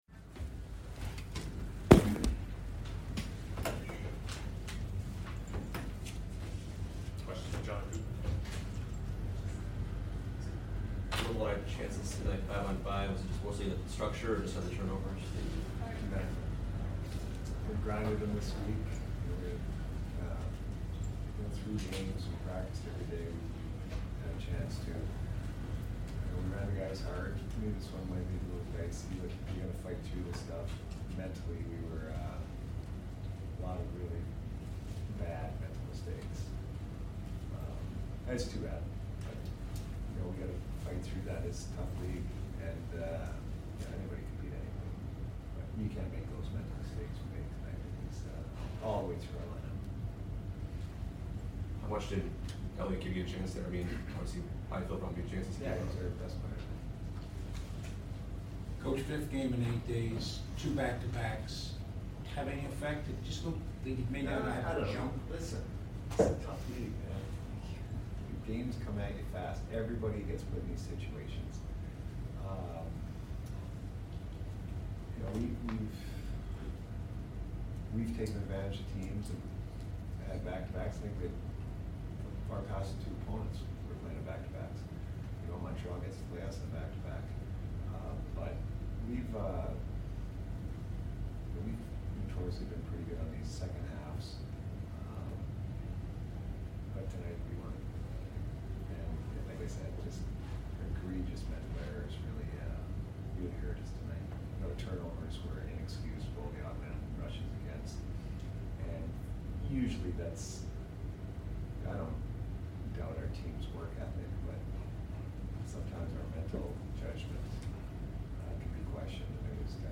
Head Coach Jon Cooper Post Game Vs MTL 4 - 2-2022